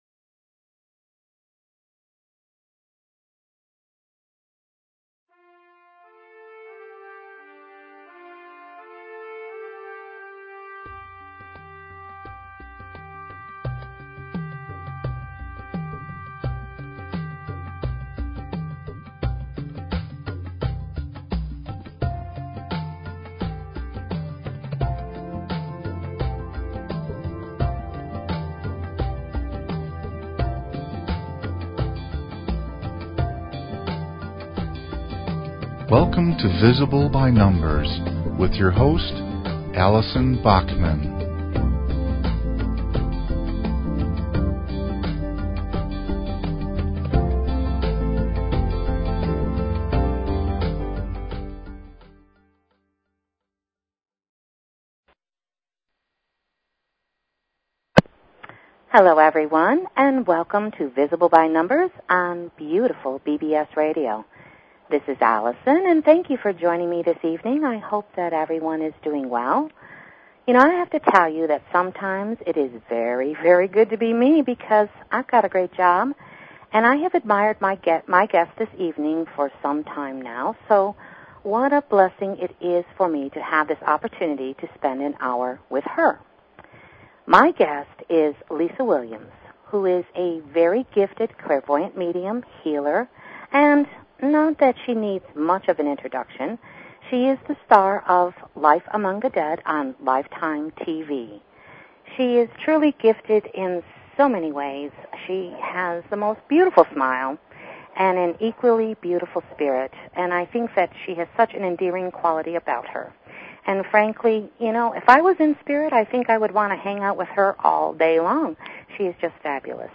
Talk Show Episode, Audio Podcast, Visible_By_Numbers and Courtesy of BBS Radio on , show guests , about , categorized as
Lisa Williams, Medium and Clairvoyant, and popular star of "Life Among the Dead" as seen on Lifetime TV as a special guest.